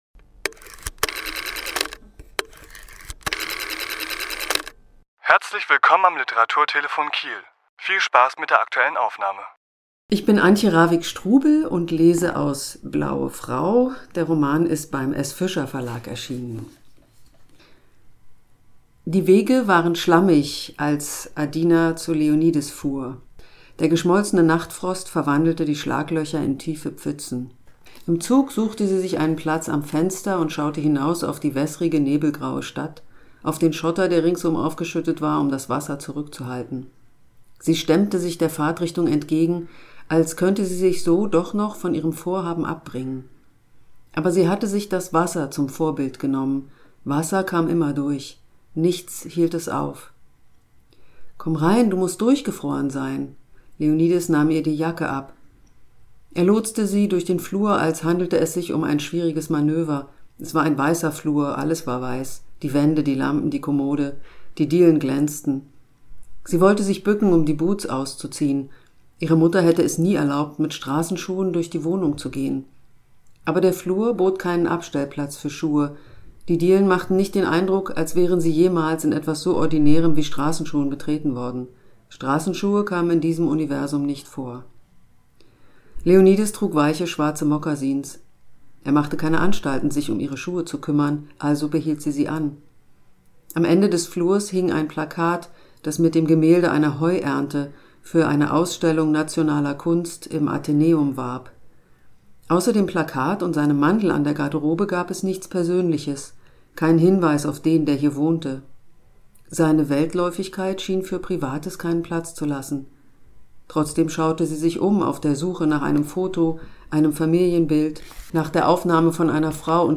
Autor*innen lesen aus ihren Werken
Die Aufnahme entstand im Rahmen einer Lesung am 18.2.2022 im Literaturhaus Schleswig-Holstein.